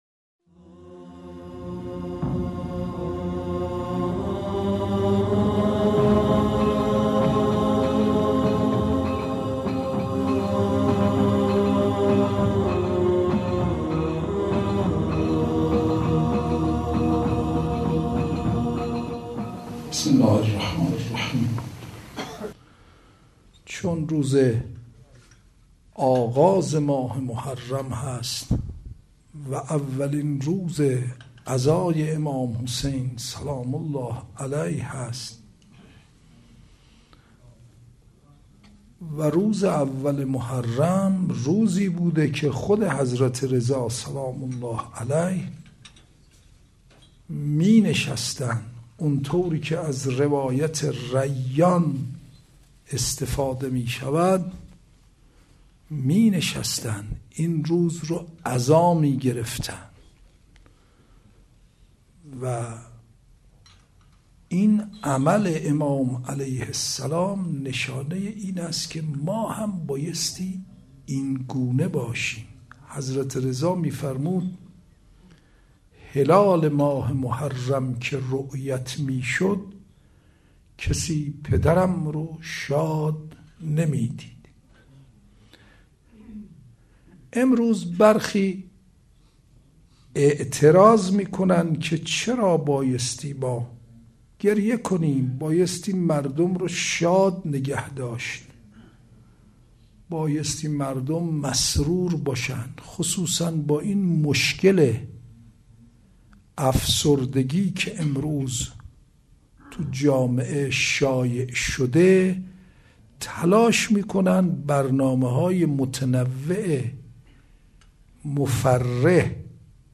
مراسم ایام عاشورا 1436 هجری قمری روز اول | سایت رسمی دفتر حضرت آيت الله العظمى وحيد خراسانى